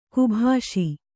♪ kubhāṣi